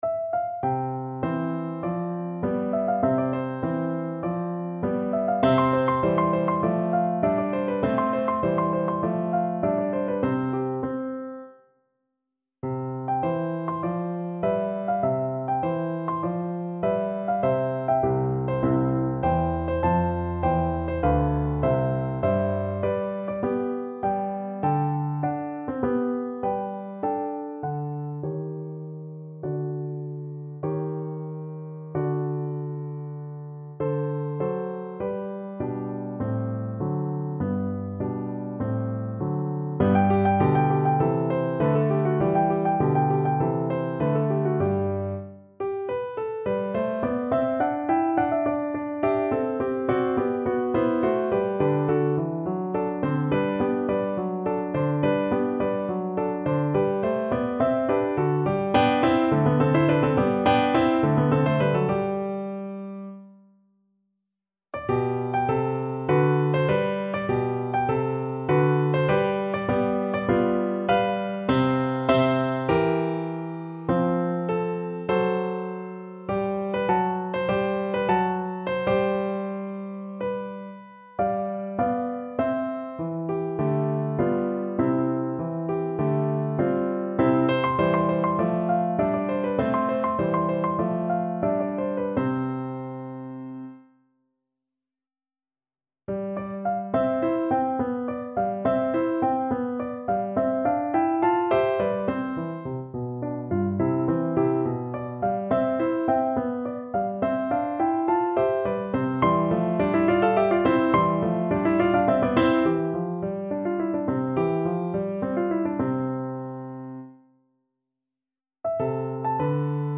Play (or use space bar on your keyboard) Pause Music Playalong - Piano Accompaniment Playalong Band Accompaniment not yet available transpose reset tempo print settings full screen
~ = 50 Larghetto
C major (Sounding Pitch) (View more C major Music for Violin )
Classical (View more Classical Violin Music)